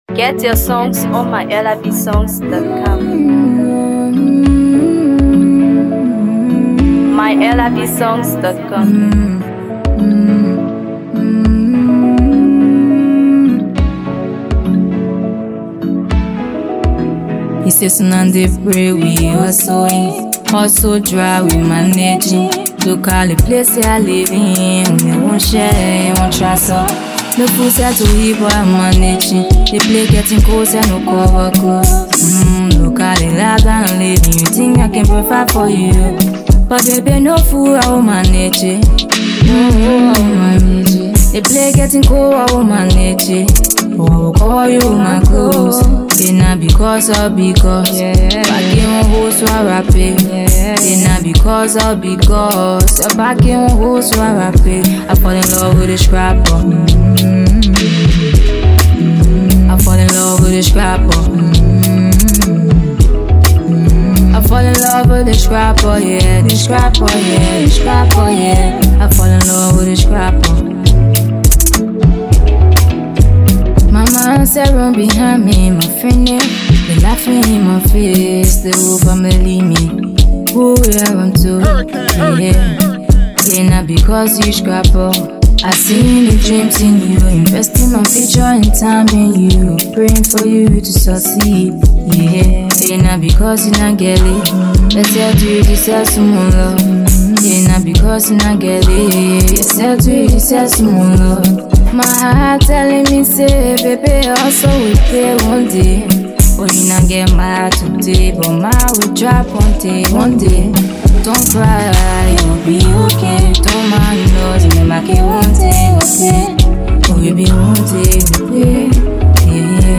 blends catchy Afrobeats rhythms with heartfelt lyrics